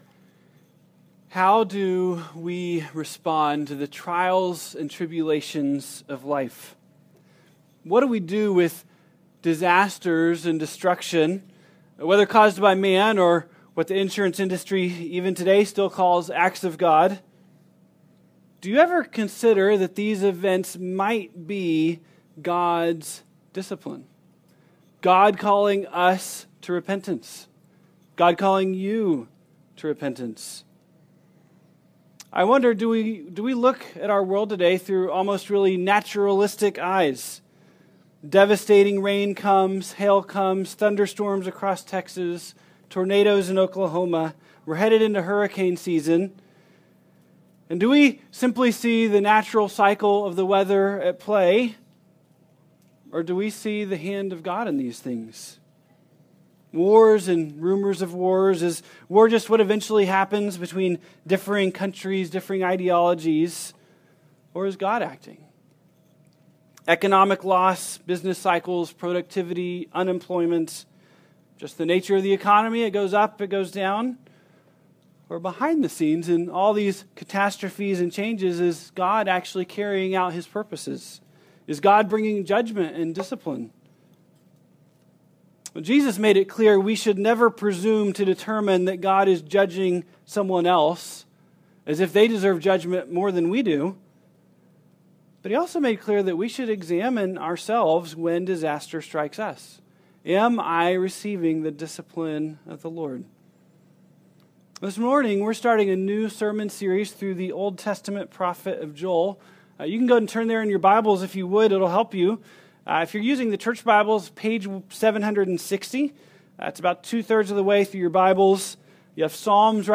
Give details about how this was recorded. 2016 ( Sunday AM ) Bible Text